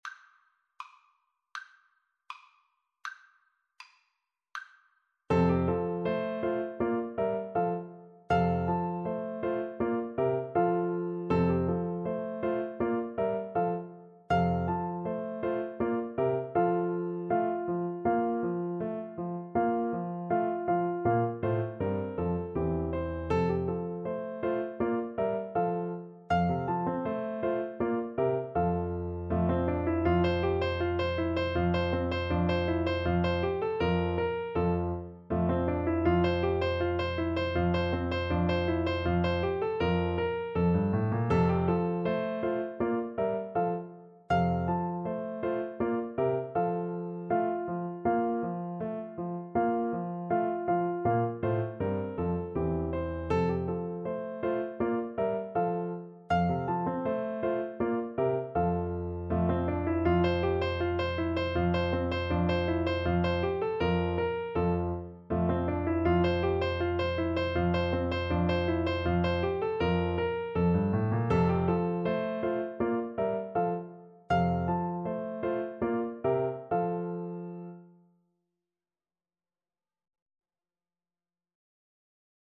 4/4 (View more 4/4 Music)
Scherzando =160